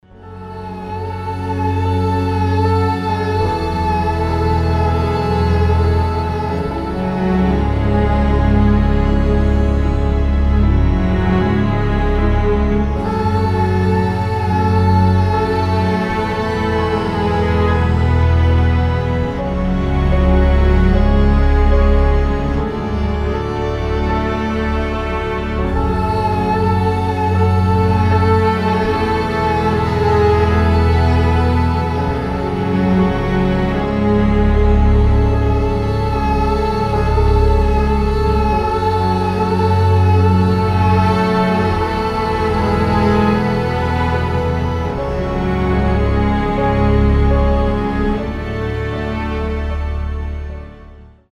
ost , инструментальные
пугающие
атмосферные